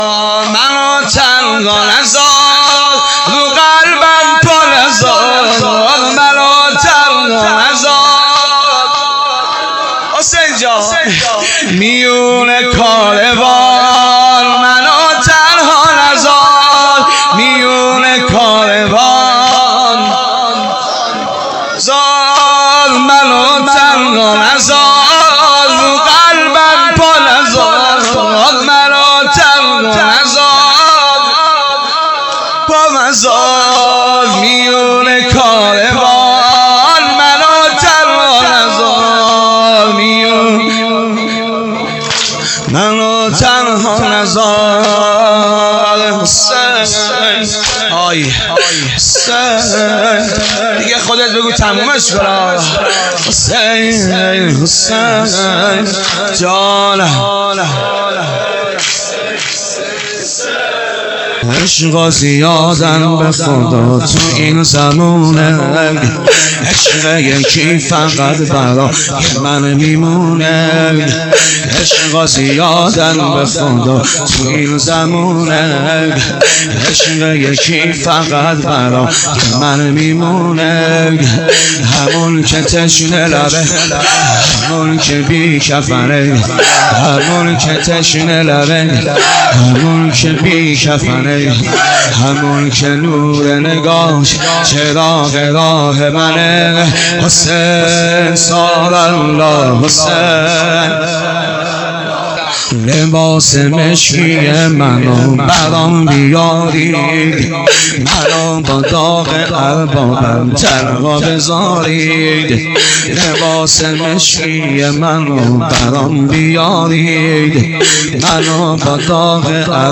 منو تنها نزار رو قلبم پانزار -سینه زنی سنگین